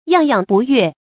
怏怏不悦 yàng yàng bù yuè
怏怏不悦发音